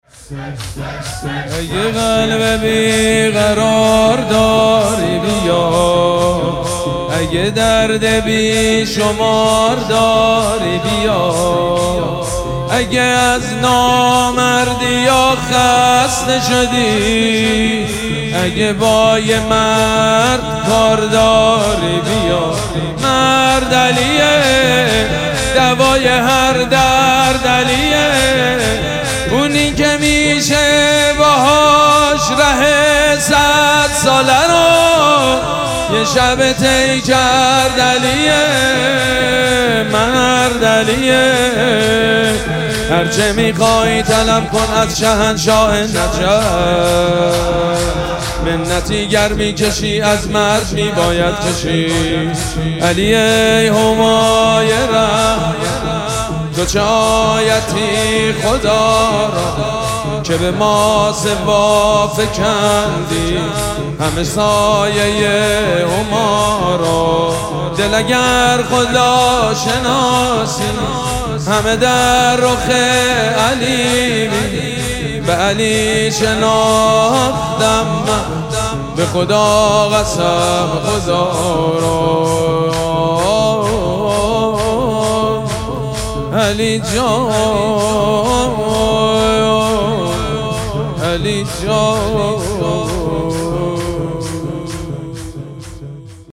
شب سوم مراسم عزاداری دهه دوم فاطمیه ۱۴۴۶
شور
مداح
حاج سید مجید بنی فاطمه